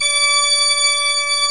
Index of /90_sSampleCDs/AKAI S6000 CD-ROM - Volume 1/VOCAL_ORGAN/CHURCH_ORGAN
ORG D5MF  -S.WAV